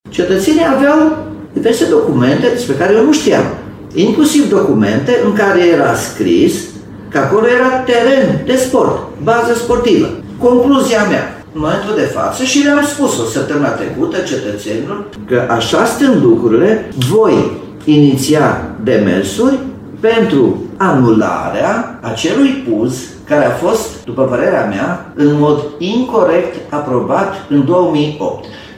Acesta a fost anunțul făcut astăzi, de primarul Nicolae Robu: